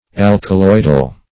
alkaloidal - definition of alkaloidal - synonyms, pronunciation, spelling from Free Dictionary
\Al`ka*loid"al\ ([a^]l`k[.a]*loid"al), a. [Alkali + -oid: cf. F.